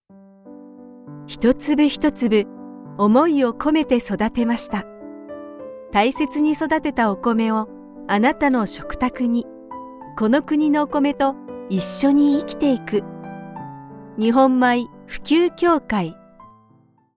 I’m a professionally trained Japanese voiceover artist with over 20 years of experience in commercials, corpor...
Conversational
Warm
Childish